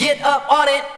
VOX SHORTS-1 0013.wav